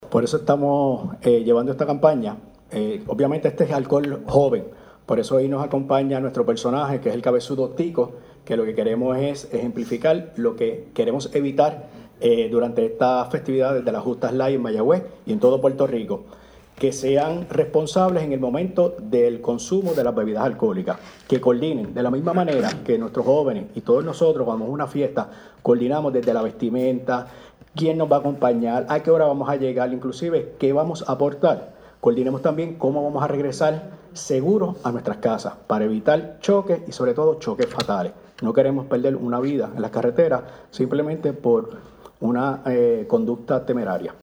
CST-EXHORTACION.mp3